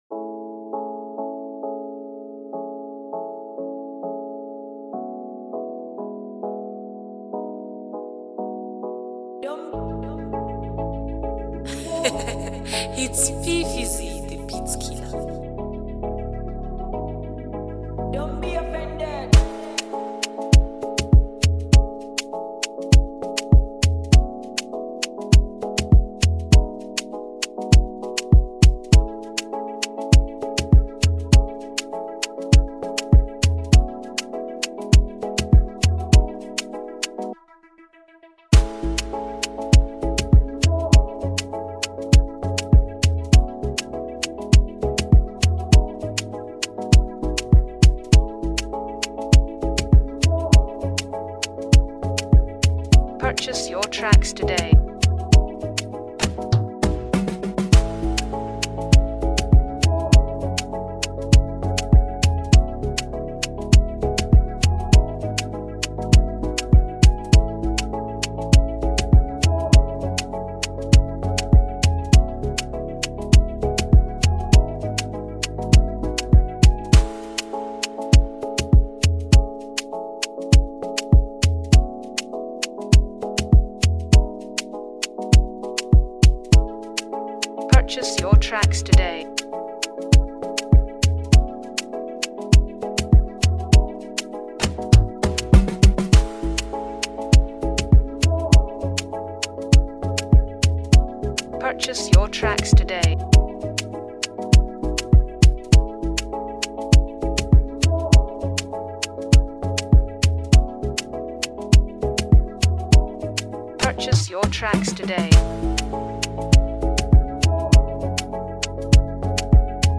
Energetic Afrobeat Instrumental
’ the production channels the vibrant energy of Afrobeat